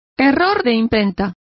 Complete with pronunciation of the translation of misprint.